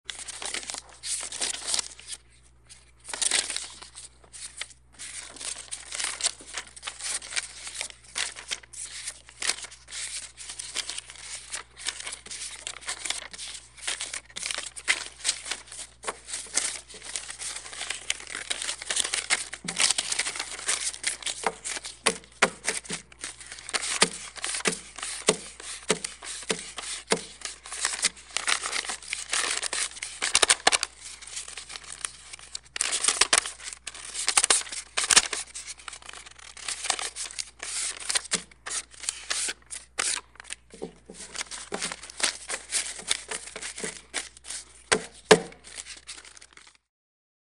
Counting banknotes manually 2
Sound category: Money, coins